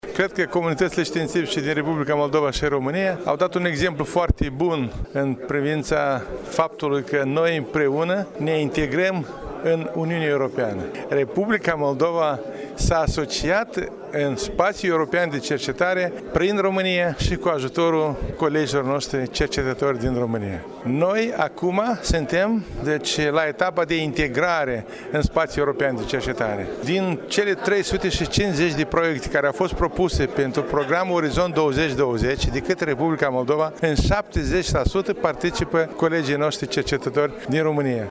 Zilelele Universităţii Apollonia din Iaşi sunt organizate sub egida Centenarului Întregirii Neamului şi reunesc personalităţi din România, Republica Moldova şi Regiunea Cernăuţi din Ucraina.